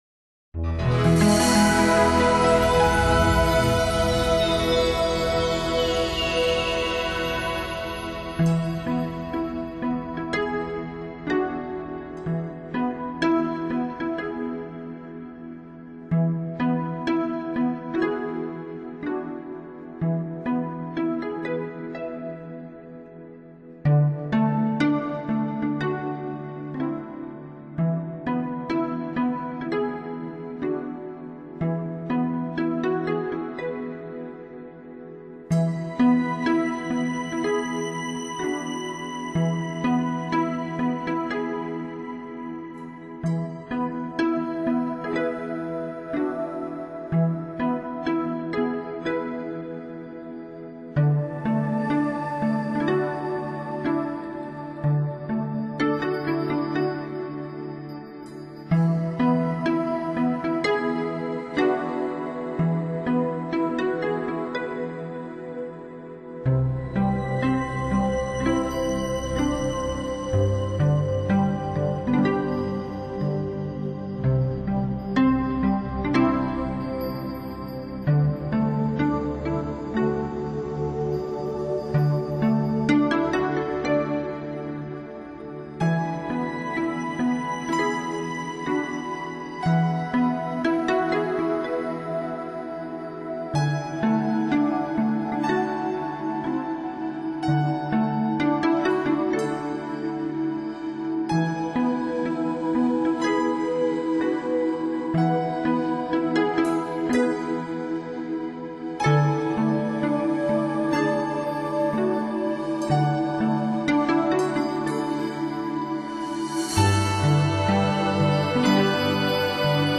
本辑是专为配合中轮七脉的修习所设计的音乐，